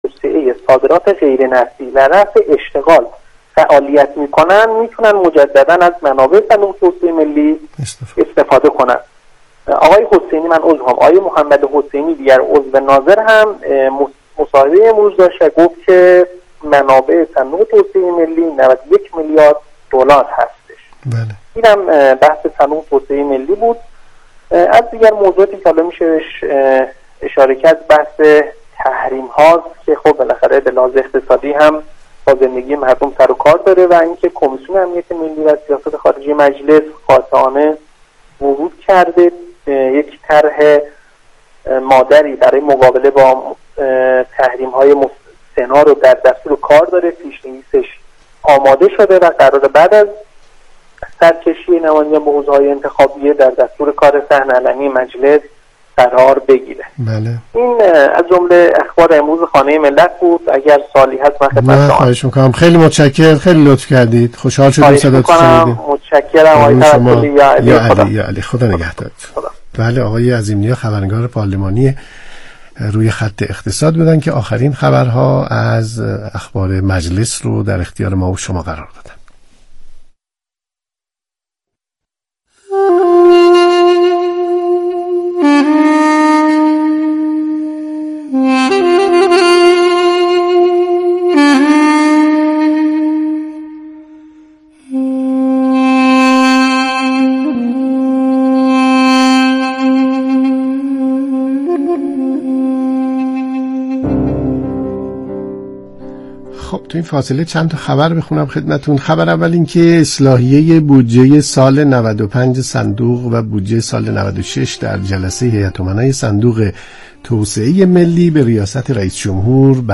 مصاحبه در خصوص تجارت ایران و کره جنوبی